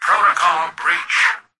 "Protocol breach" excerpt of the reversed speech found in the Halo 3 Terminals.